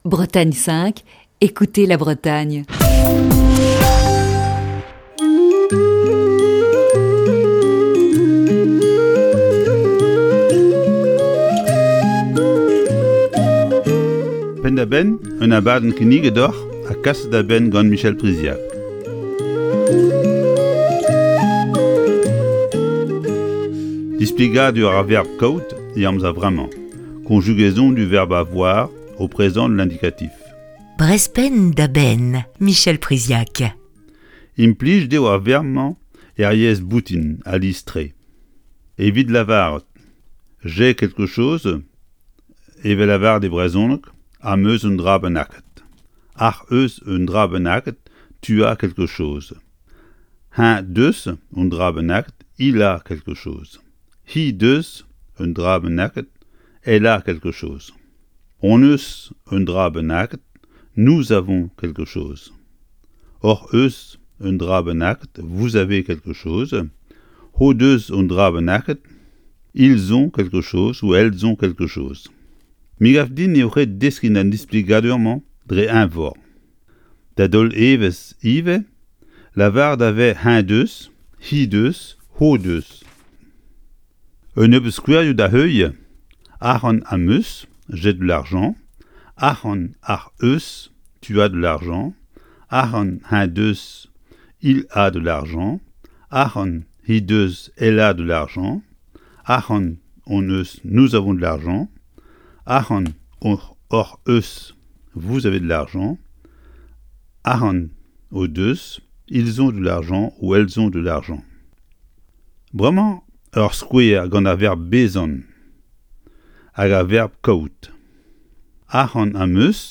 Un peu de conjugaison ce matin dans Breizh Penn da Benn.